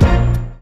Shady_Hit_1.wav